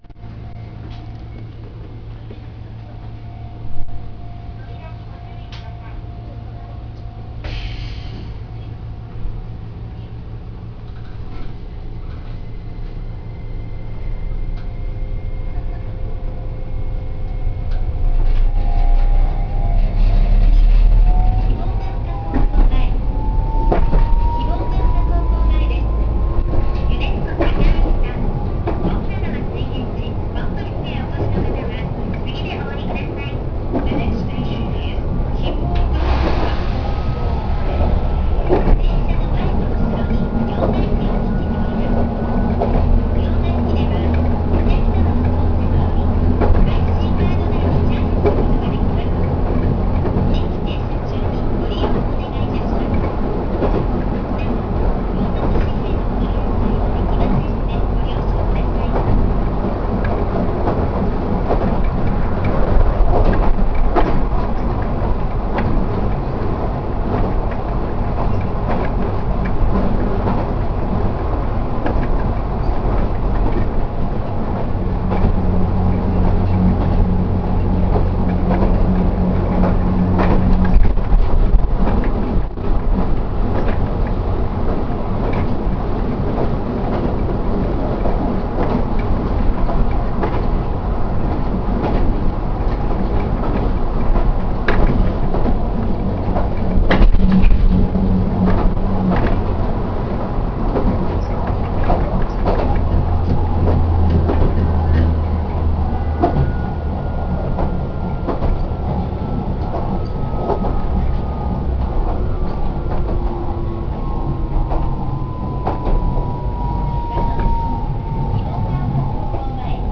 〜車両の音〜
・5000形走行音
【筑豊電鉄線】筑豊香月→希望ヶ丘高校前（2分16秒：743KB）
筑豊電鉄にとって初めてとなるVVVFインバータ制御車となりました。低床車両になったこともあり、他の車両とは全く異なる車両となったイメージ。